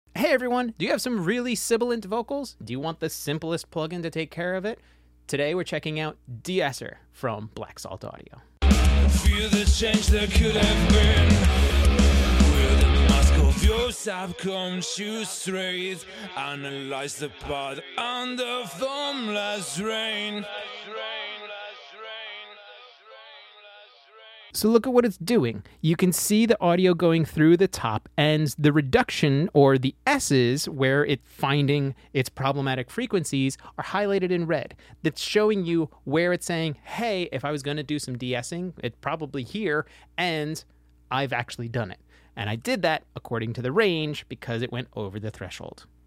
DSR Mp3 Sound Effect Say goodbye to sibilance! DSR from Black Salt Audio is your go-to for smooth vocals. Control threshold and range easily for crystal-clear sound.